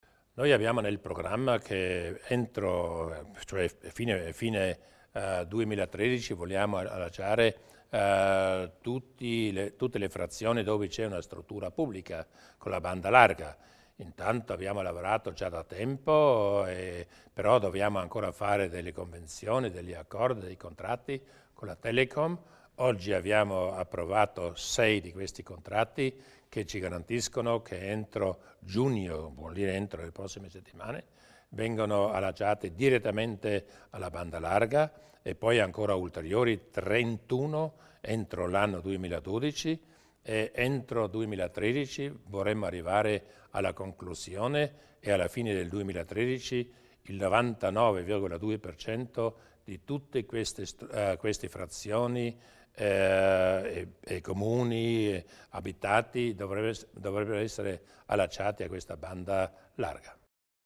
Il Presidente Durnwalder spiega l'importanza dello sportello unico